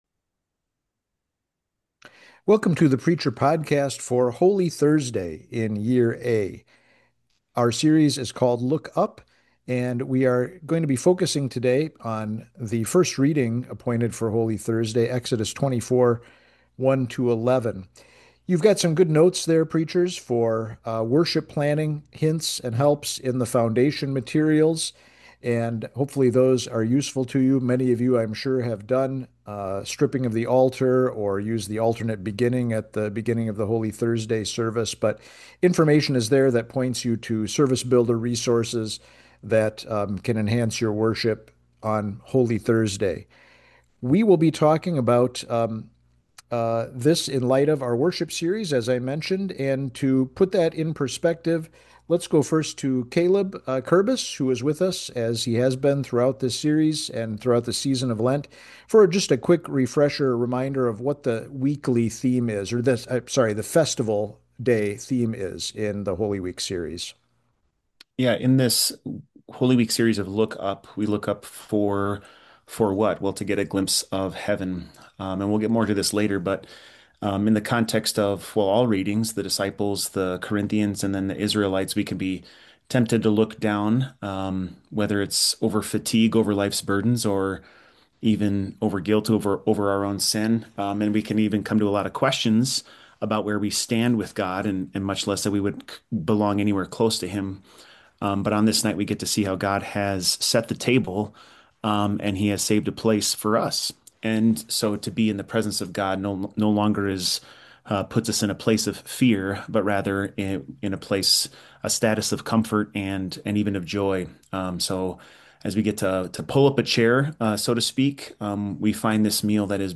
Download or listen to multiple pastors discuss sermon topics for Holy Week - Look Up Podcast: